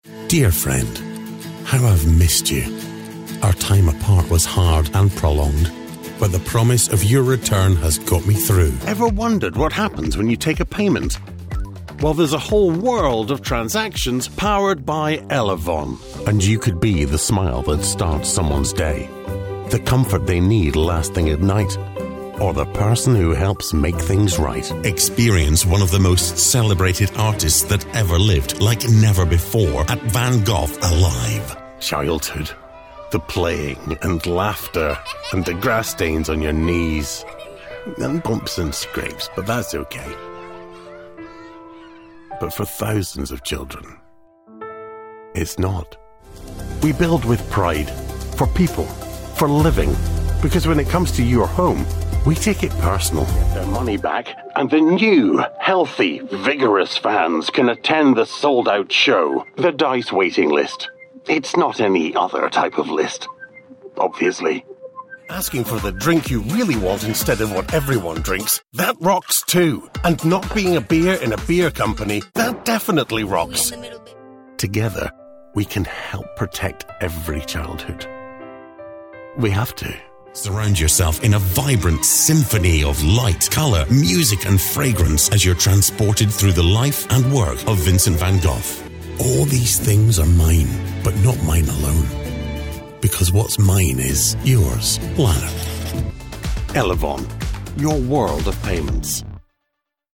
Inglés (escocés)
Esto me da un tono muy suave...
Tranquilizador
Seguro
Paternal